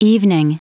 Prononciation du mot evening en anglais (fichier audio)
Prononciation du mot : evening
evening.wav